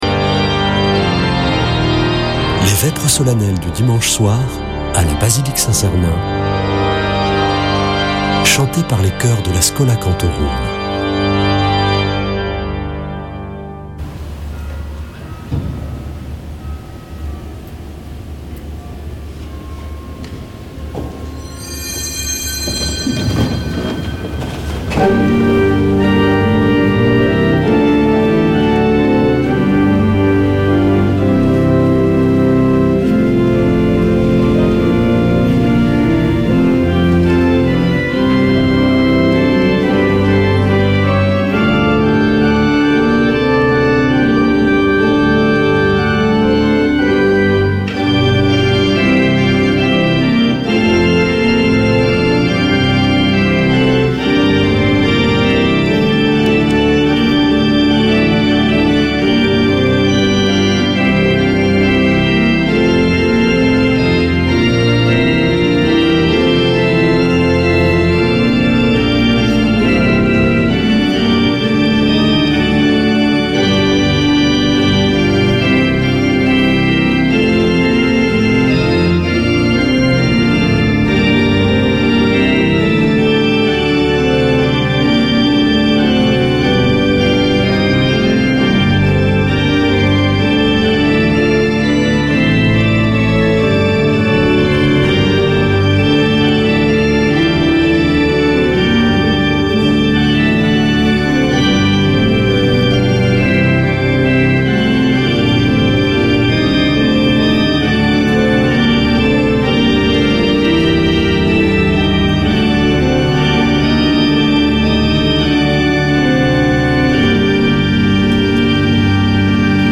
Vêpres de Saint Sernin du 24 sept.
Schola Saint Sernin Chanteurs